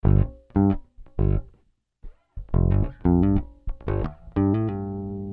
Hiphop music bass loop - 90bpm 72